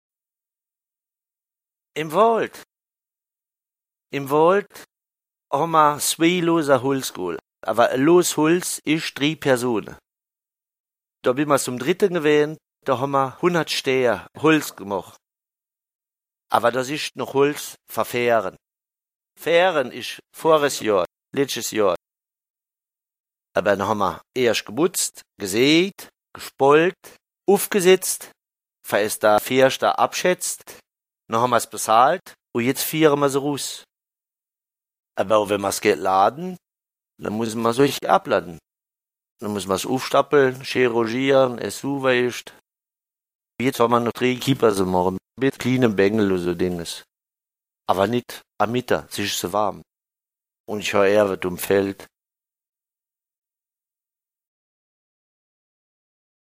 Contes et récits en ditsch enregistrés dans les communes de Guessling, Lelling, Vahl-Ebersing, Maxstadt-Réning, Laning et Altrippe.